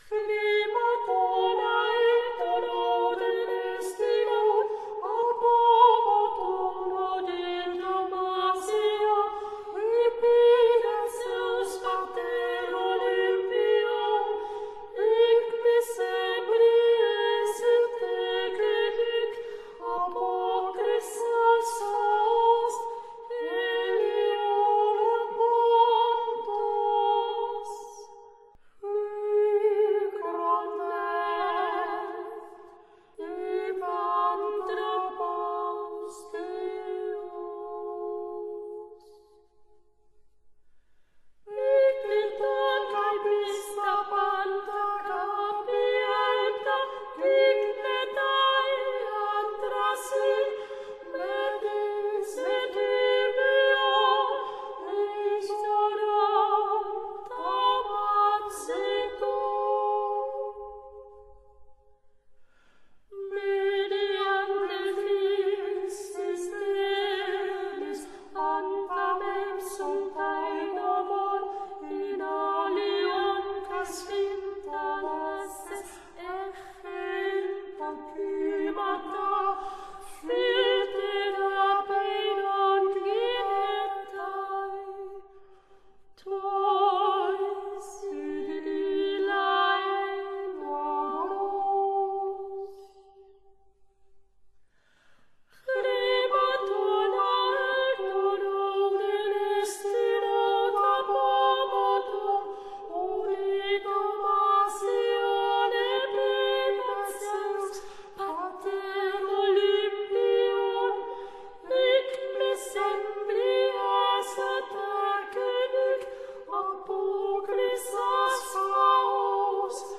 Música-Griega-Antigua-para-Genially.mp3